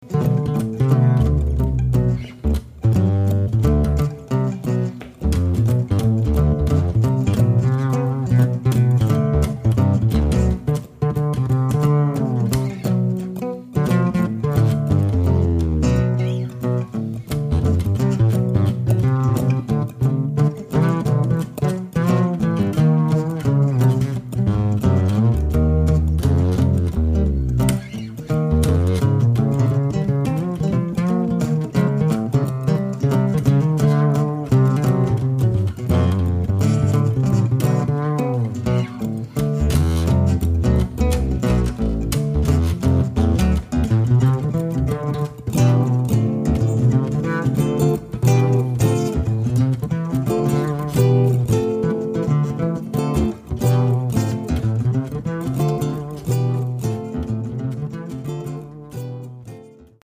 pour trio acoustique
Bass solo
Guitar solo